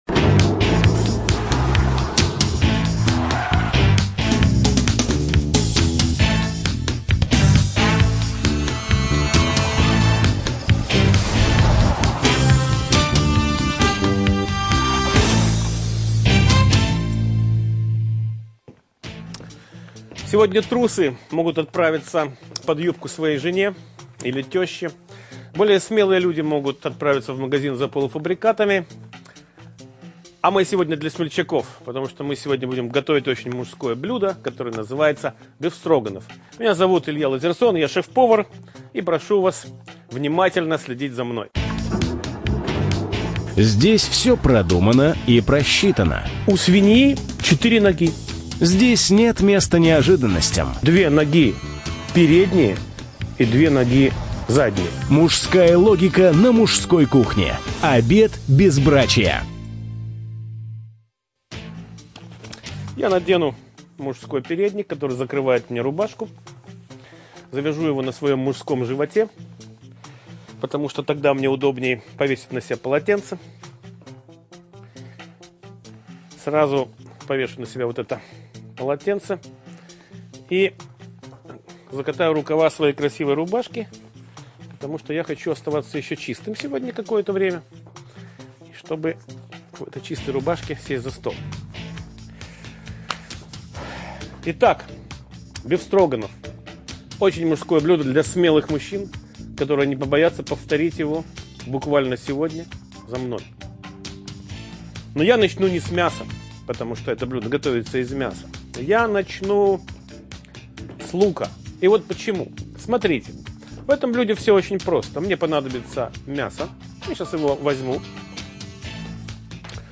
ЧитаетЛазерсон И.